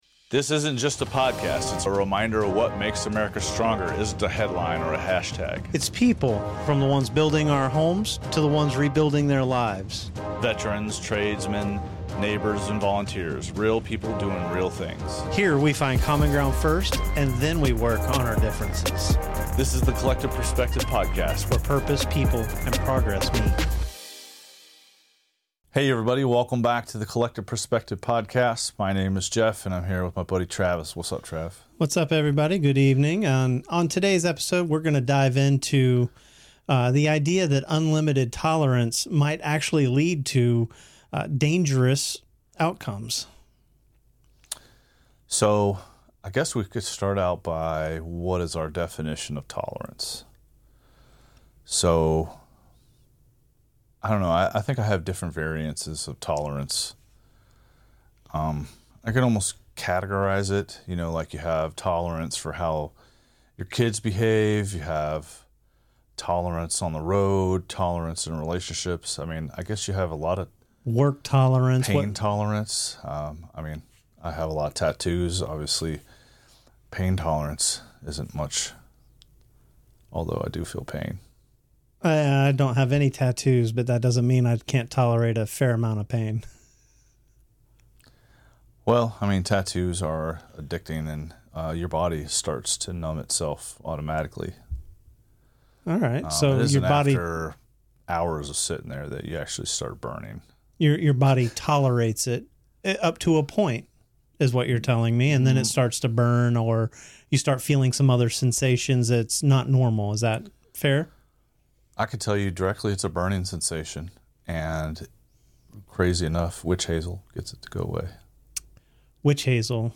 The conversation is honest, grounded, and sometimes funny but always focused on building stronger communities.